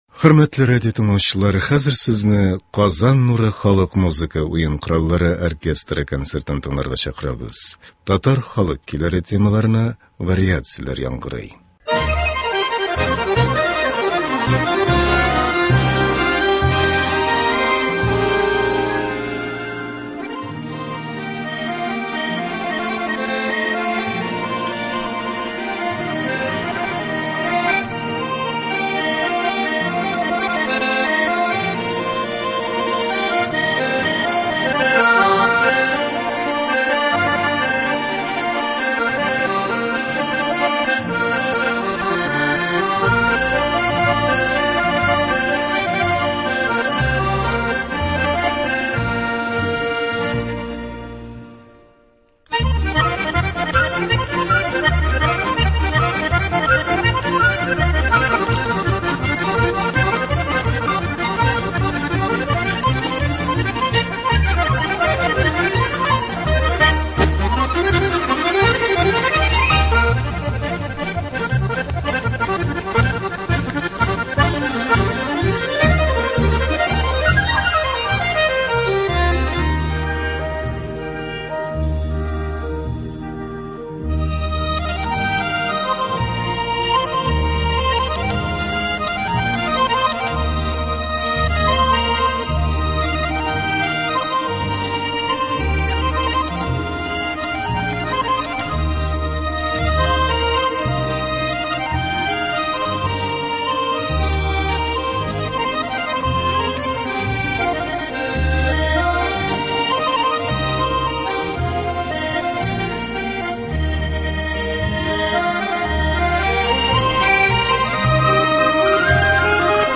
“Казан нуры” халык уен кораллары оркестры концерты.